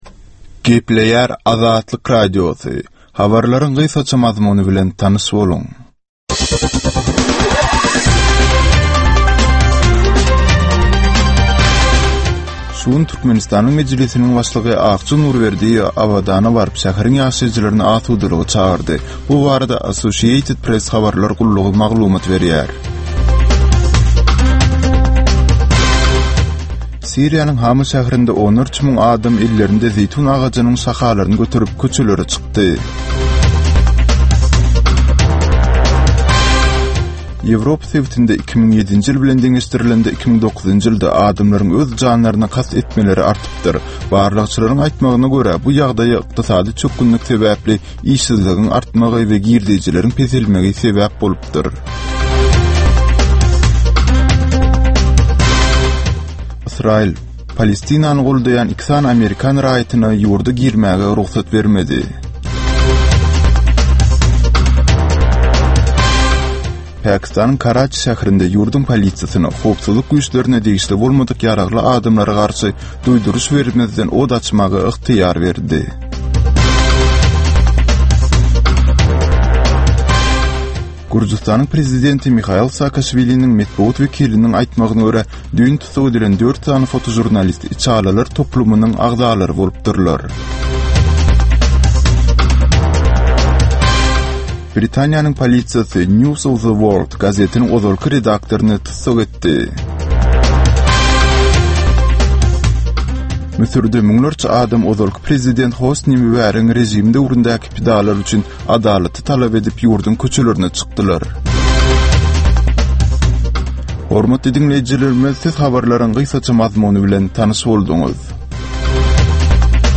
Türkmenistandaky we halkara arenasyndaky möhüm wakalar we meseleler barada ýörite informasion-analitiki programma. Bu programmada soňky möhüm wakalar we meseleler barada analizler, synlar, söhbetdeşlikler, kommentariýalar we diskussiýalar berilýär.